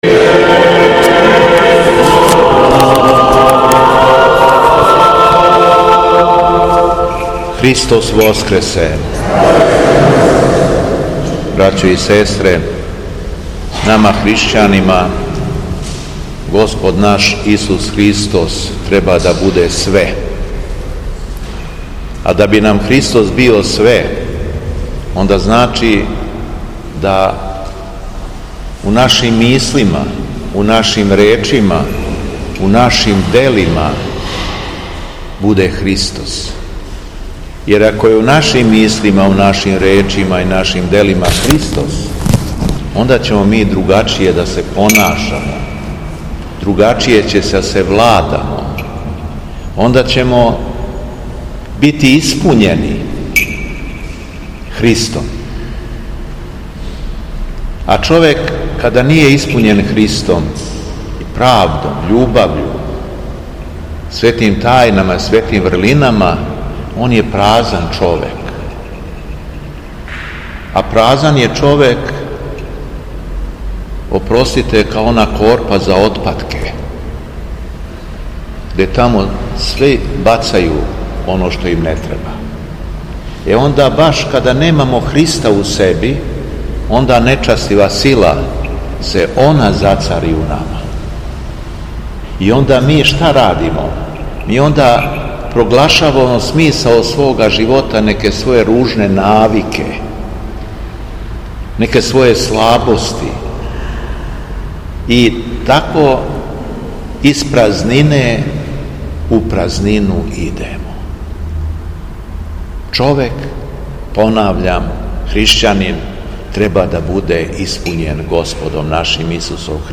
Беседа Његовог Преосвештенства Епископа шумадијског г. Јована
За певницом је било братство храма са верним народом.